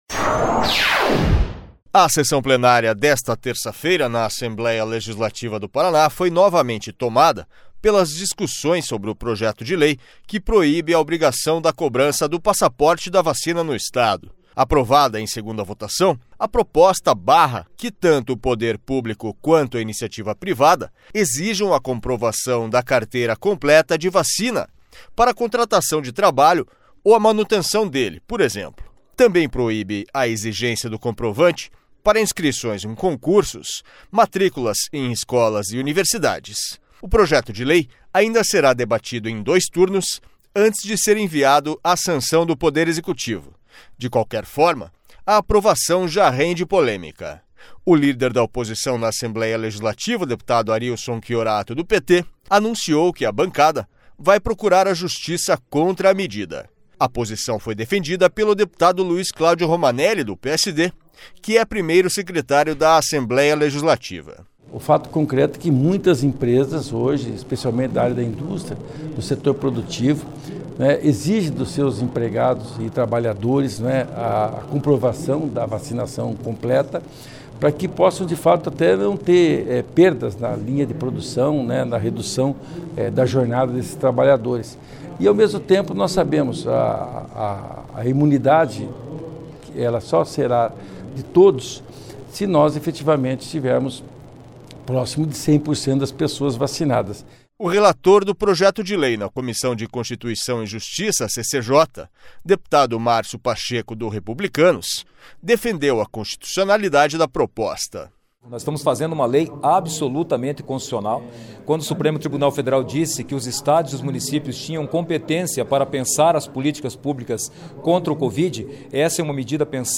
SONORA LUIZ CLÁUDIO ROMANELLI
SONORA MÁRCIO PACHECO
SONORA ADEMAR TRAIANO